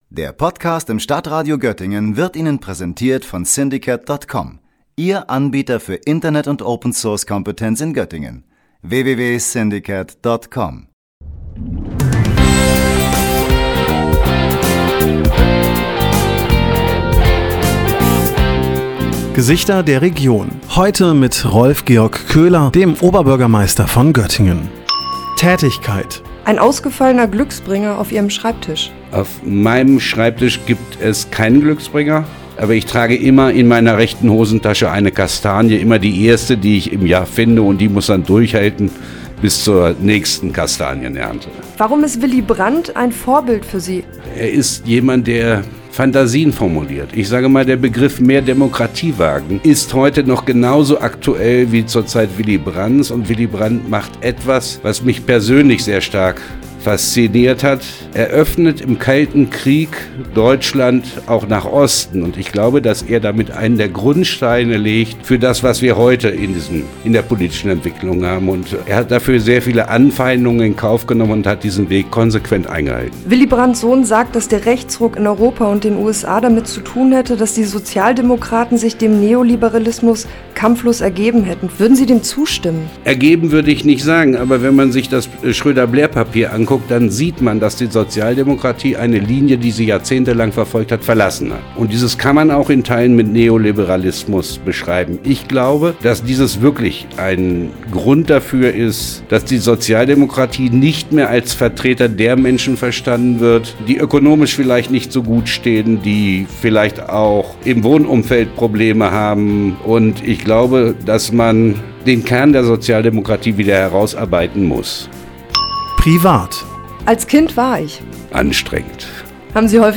Bereits seit 2014 bekleidet unser heutiger Gast das Amt des Göttinger Oberbürgermeisters und lenkt die Geschicke dieser Stadt.